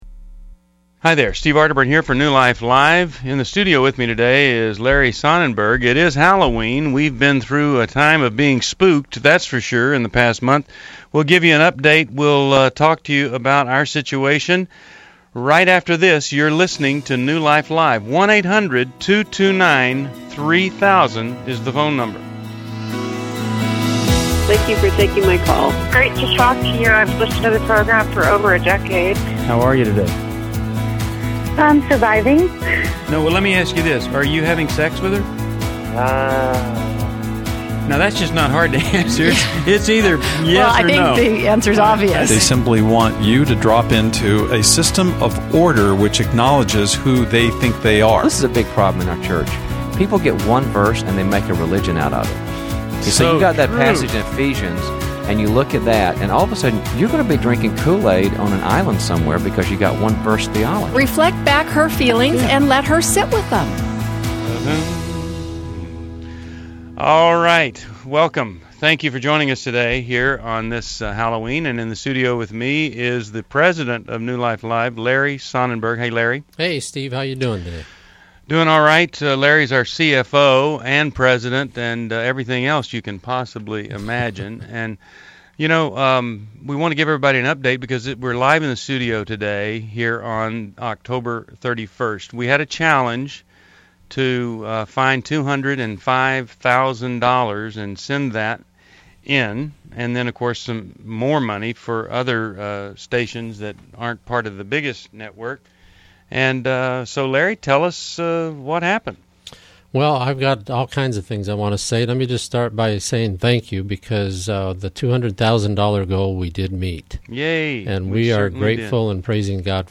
Explore insights on support, marriage, and navigating homosexuality as callers share their experiences and seek guidance on New Life Live: October 31, 2011.